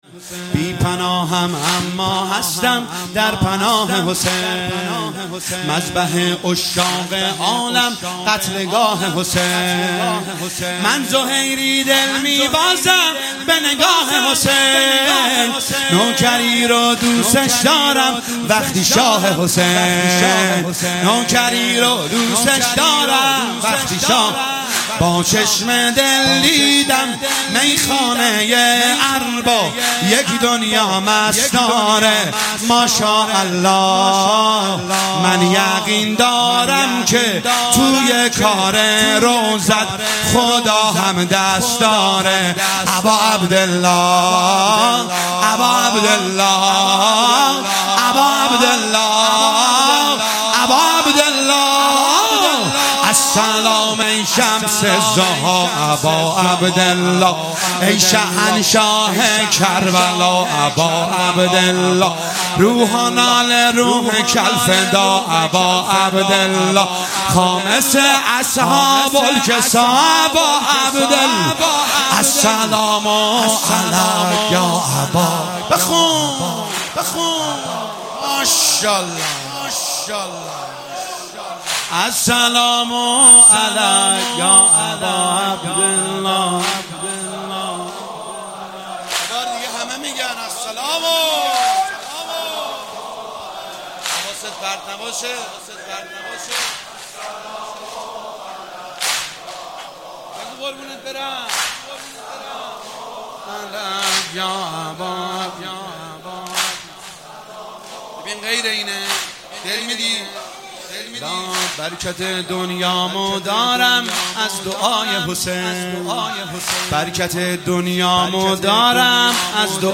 شب ششم محرم1401
واحد تند